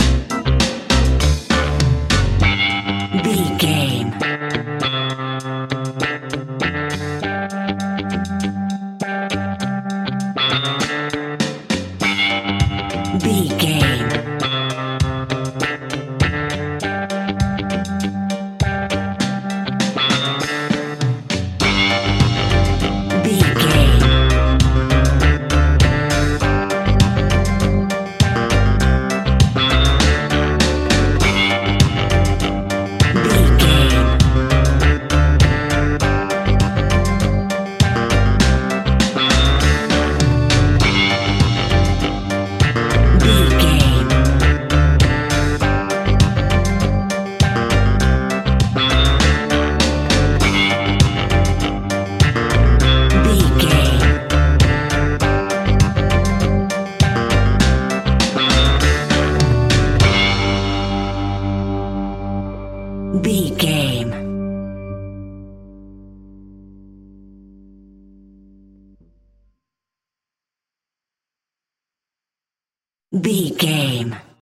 Uplifting
Aeolian/Minor
reggae instrumentals
laid back
off beat
drums
skank guitar
hammond organ
percussion
horns